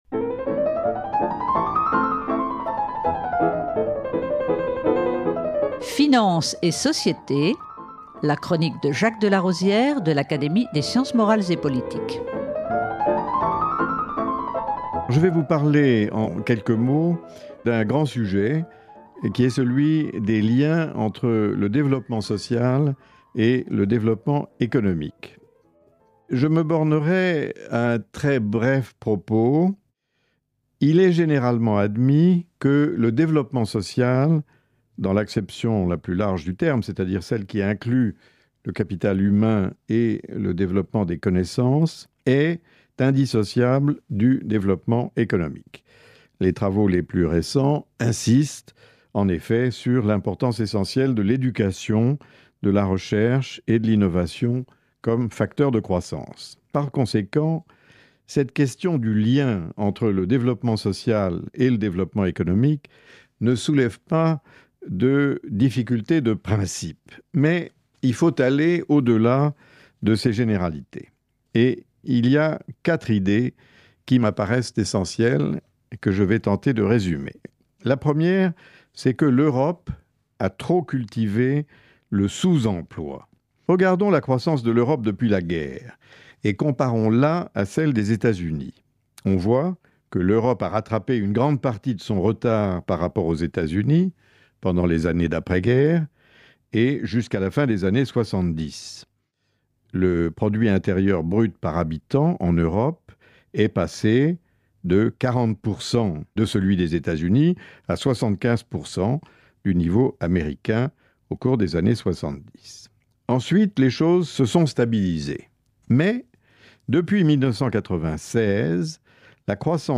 Avec Jacques de LAROSIÈRE
Jacques de Larosière reprend pour cette chronique "Finances et Société" sur Canal Académie, les propos introductifs qu'il a tenus en présidant une importante table ronde à l'occasion du 50 ème anniversaire de la Banque de Développement du Conseil de l'Europe (novembre 206).